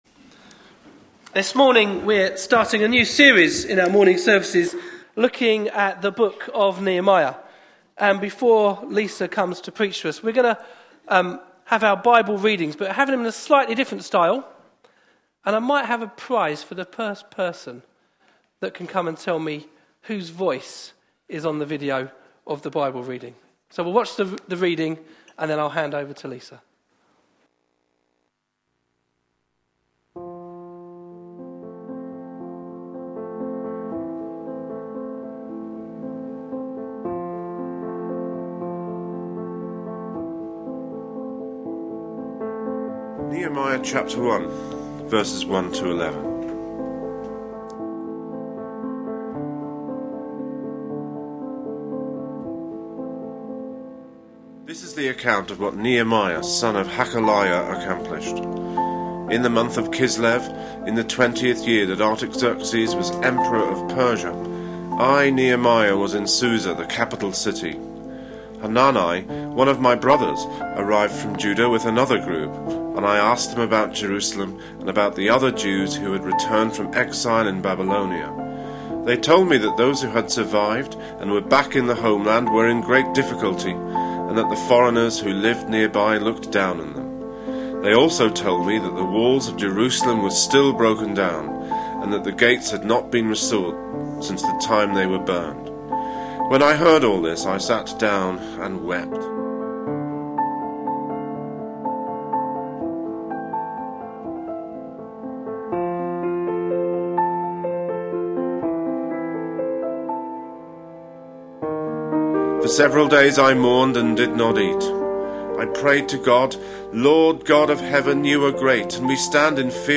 A sermon preached on 8th June, 2014, as part of our Another Brick In The Wall. series.